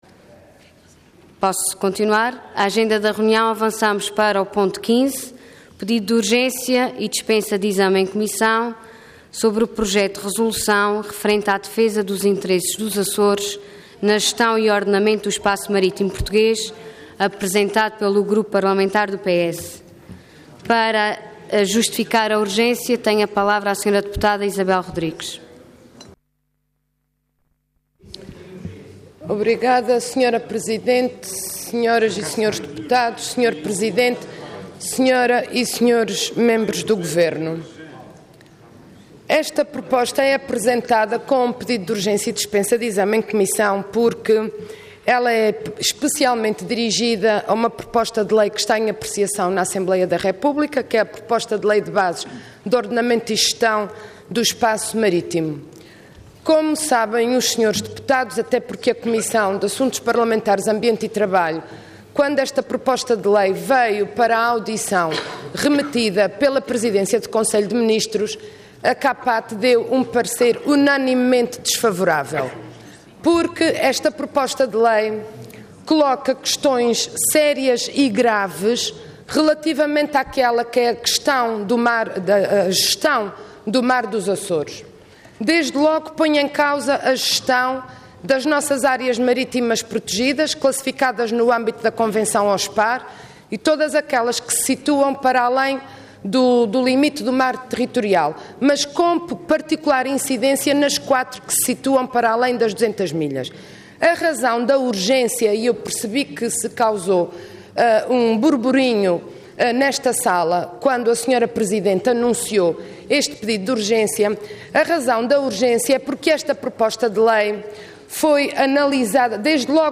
Detalhe de vídeo 16 de maio de 2013 Download áudio Download vídeo Diário da Sessão Processo X Legislatura Defesa dos interesses dos Açores na gestão e ordenamento do espaço marítimo português. Intervenção Pedido de urgência e dispensa de exame em comissão Orador Isabel Rodrigues Cargo Deputada Entidade PS